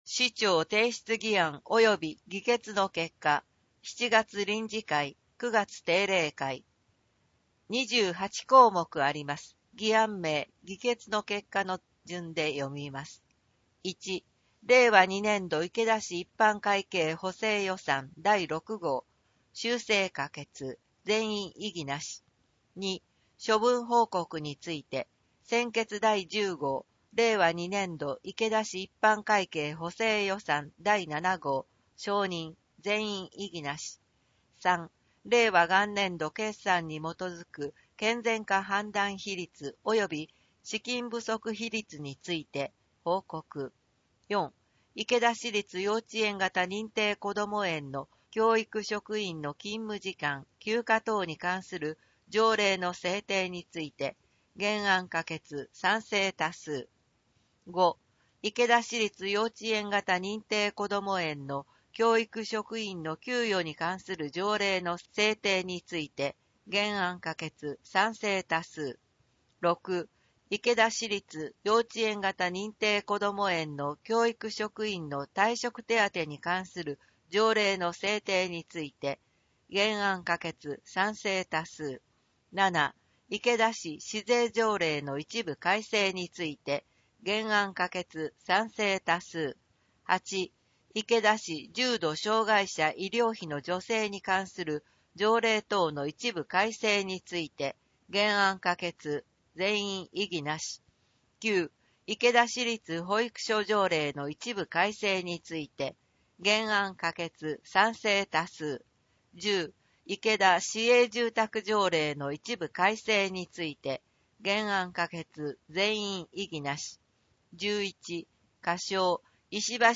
声の市議会だより